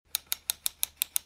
sfx-clock.f257bc48f64bad86c6e7.mp3